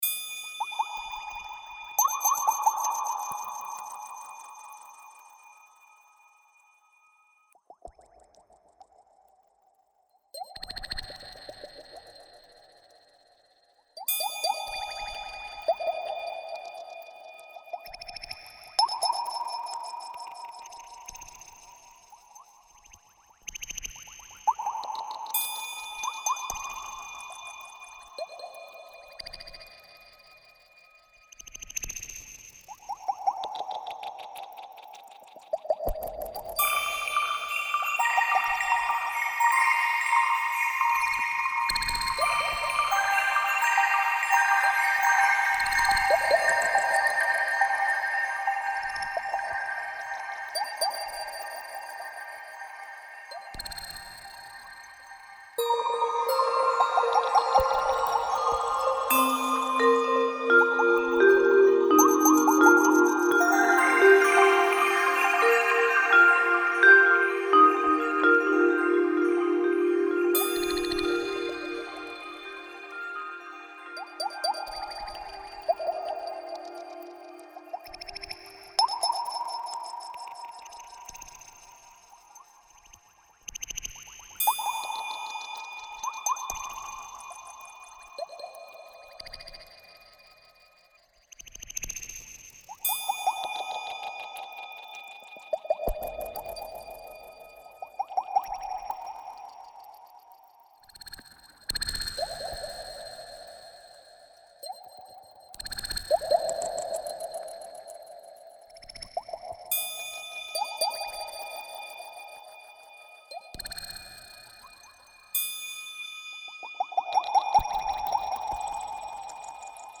for solo lever or pedal harp
with percussion